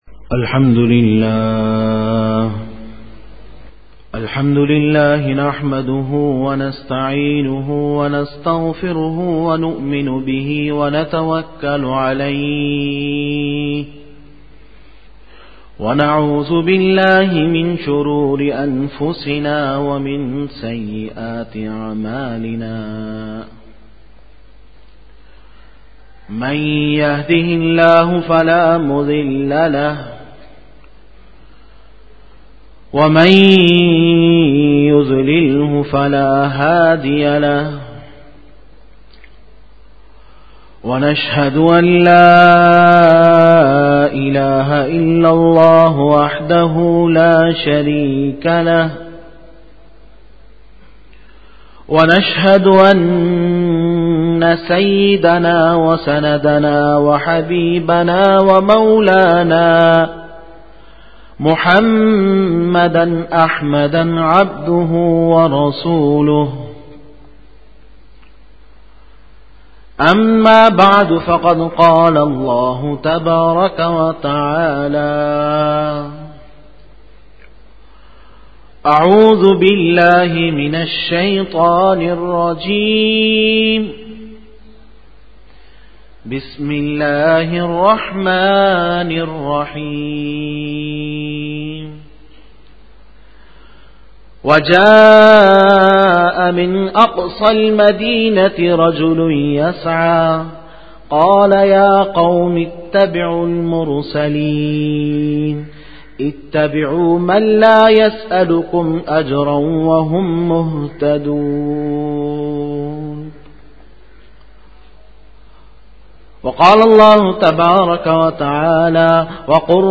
09 Bayan e juma tul mubarak 01-March-2013
Khitab-e-Jummah 2013